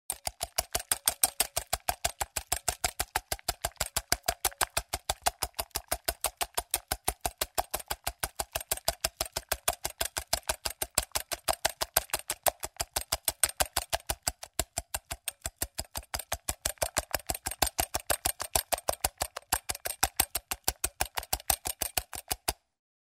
Звуки кухни, жарки
Взбивают венчиком